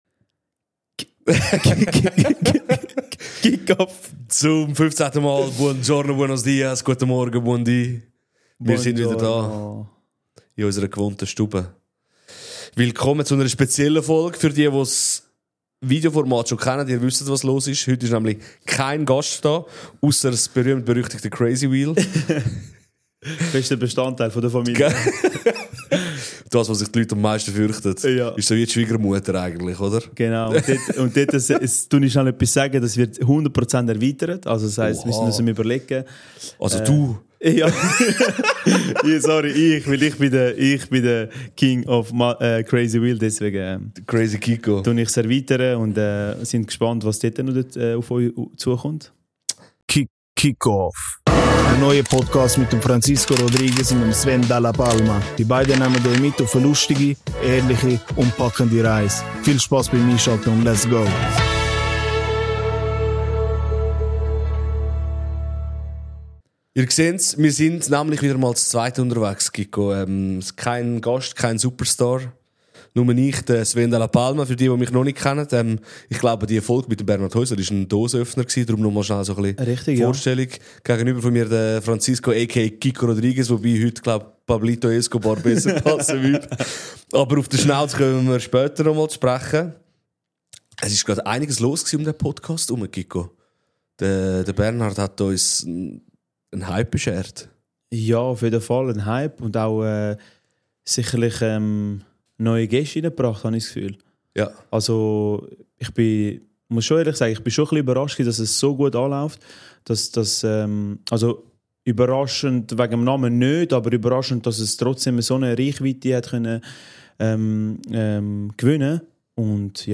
In dieser Folge gibt es eine Portion Cic-Off pur: Keine Gäste, kein Star, kein Ablenkungsmanöver – nur wir zwei.